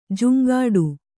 ♪ juŋgāḍu